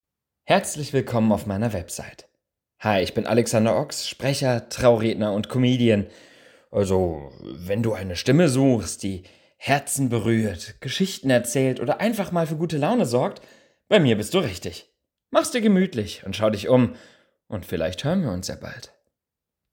Sprachnachricht-Website_mp3.mp3